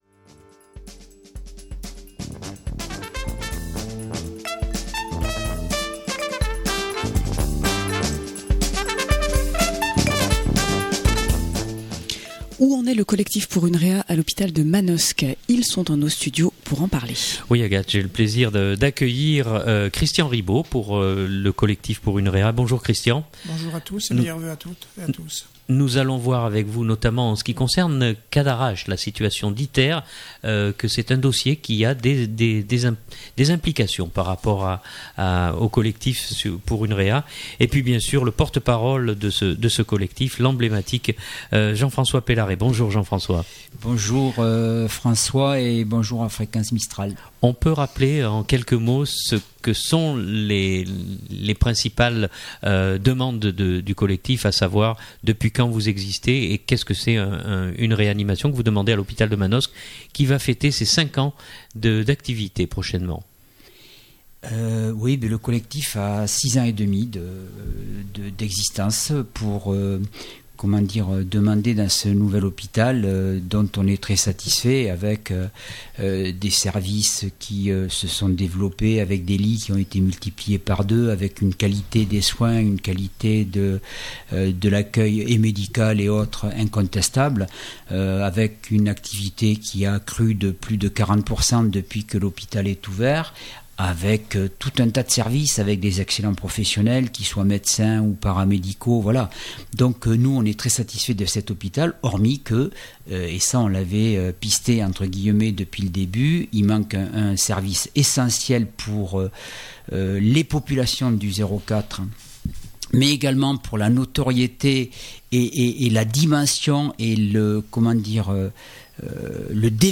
Nous recevons le collectif pour une réa à l’hôpital de Manosque.